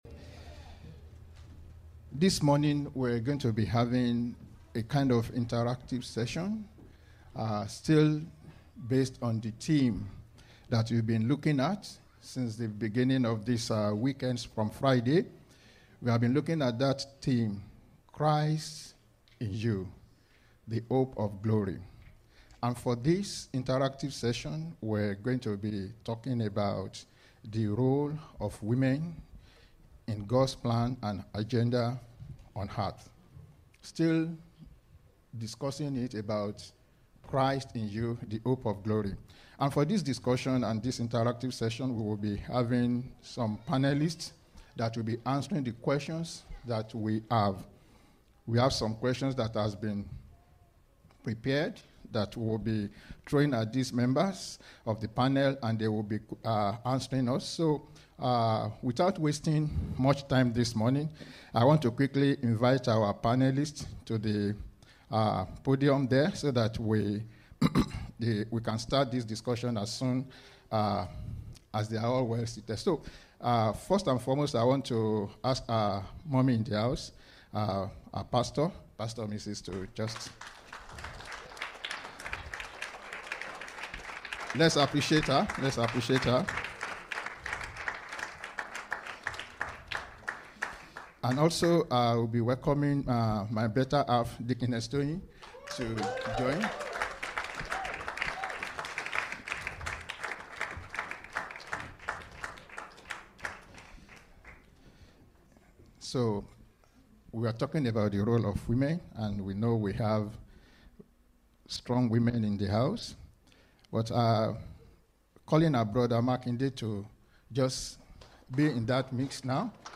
Christ in You (Panel Discussion)